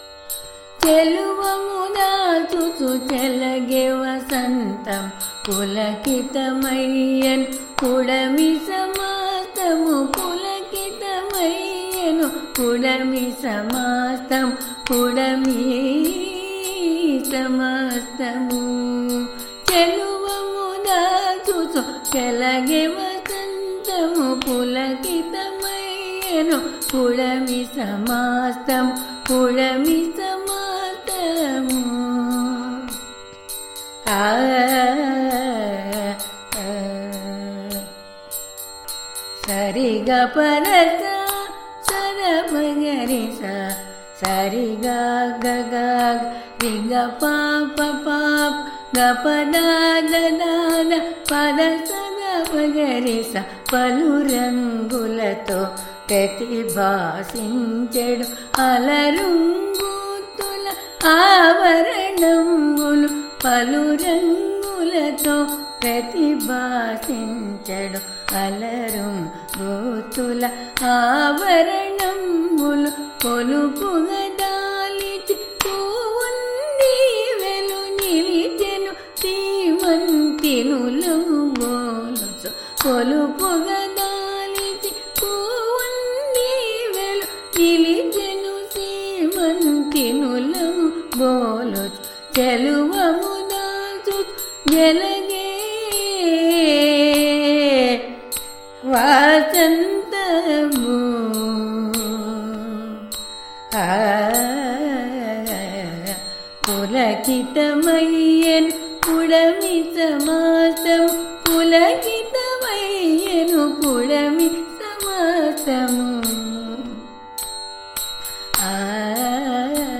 ఈ గేయాన్ని రాగమాలికగా పాడి